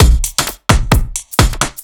OTG_Kit 4_HeavySwing_130-B.wav